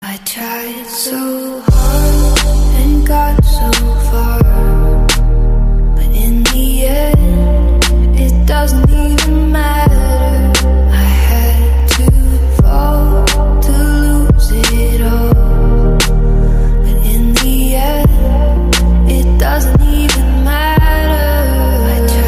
Elektroniczne